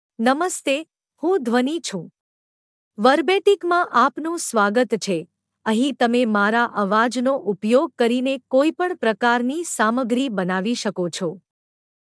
Dhwani — Female Gujarati (India) AI Voice | TTS, Voice Cloning & Video | Verbatik AI
Dhwani is a female AI voice for Gujarati (India).
Voice sample
Listen to Dhwani's female Gujarati voice.
Female